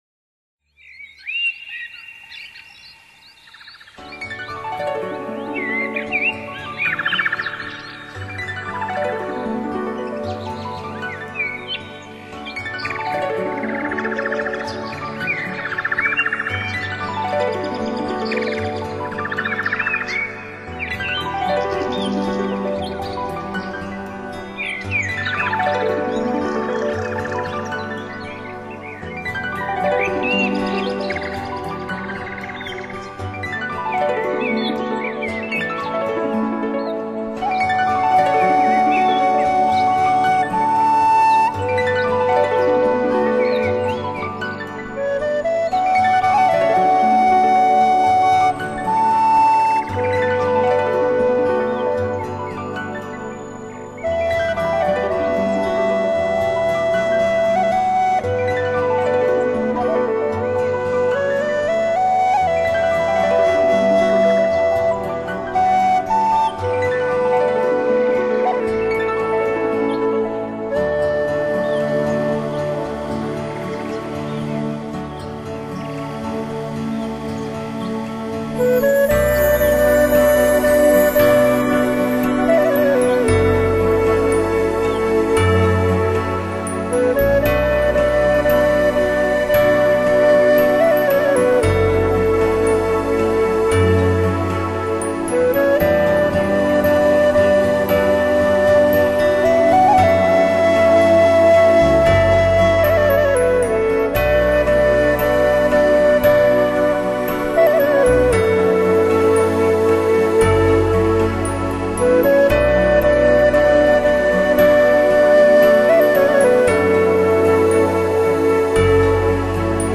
简单、乾净、空灵的氛围衬著温暖却又冷静的音乐线条，